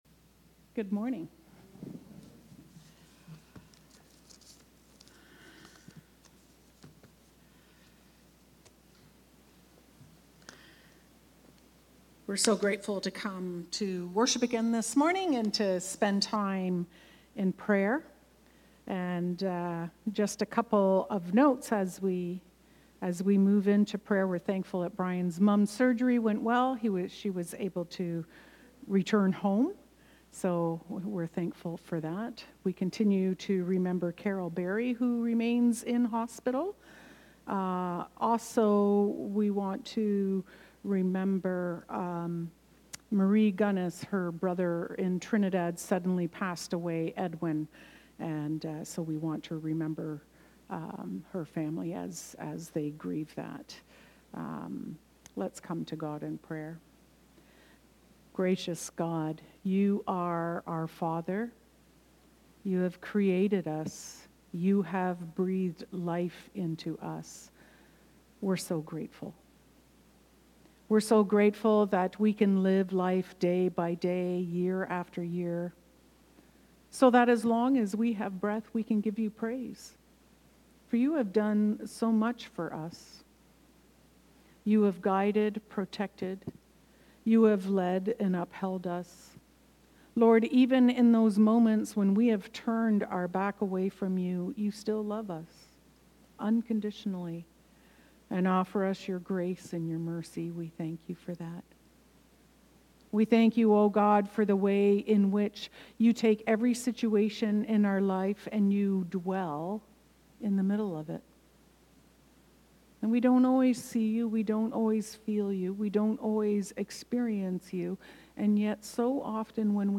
Knox Binbrook worship service